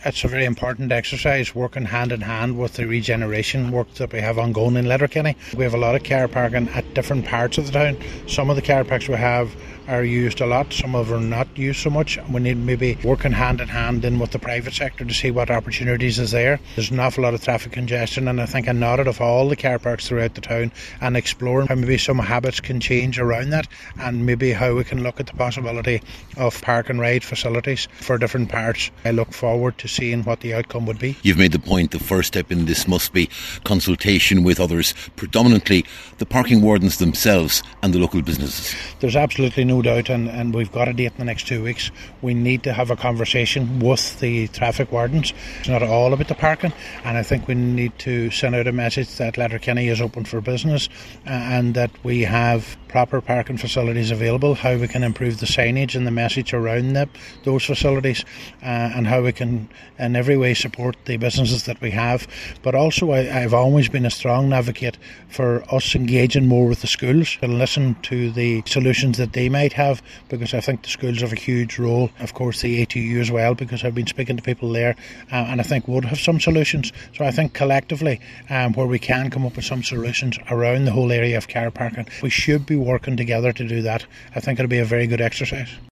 Cllr Brogan says this will be an important exercise………….